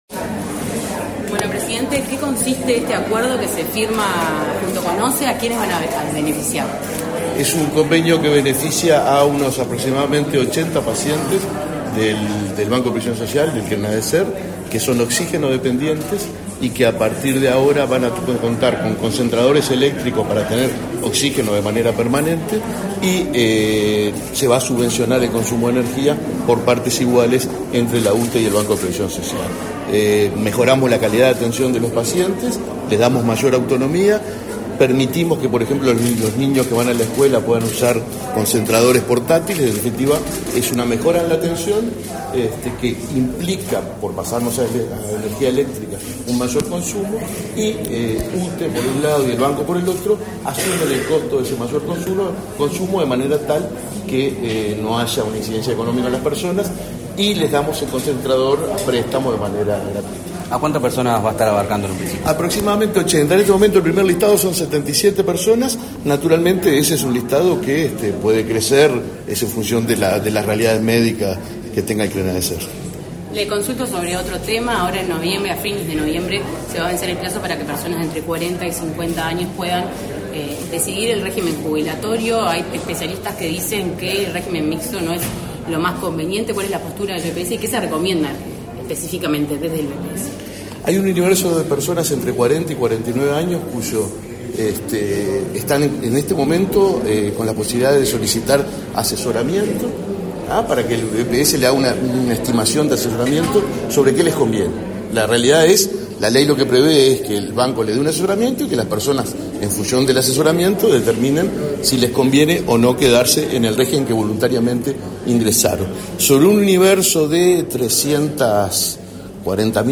Declaraciones a la prensa del presidente del BPS, Alfredo Cabrera
cabrera prensa.mp3